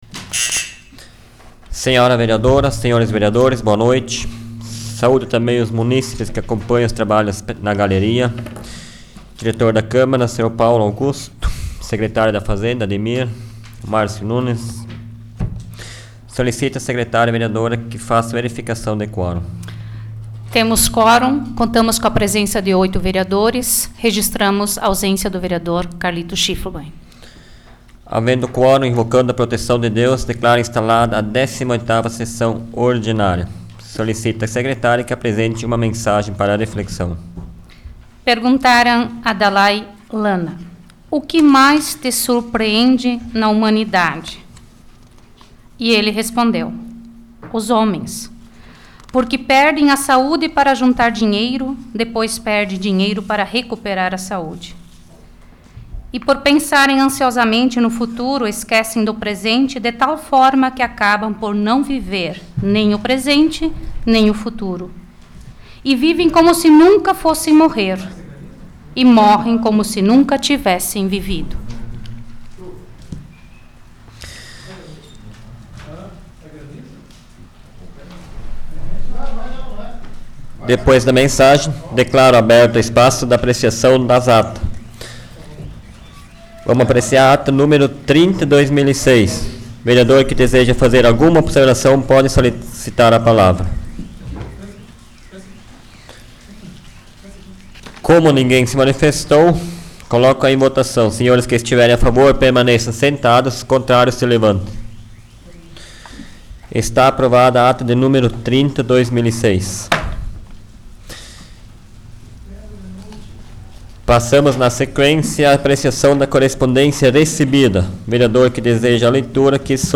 Áudio da 56ª Sessão Plenária Ordinária da 12ª Legislatura, de 14 de agosto de 2006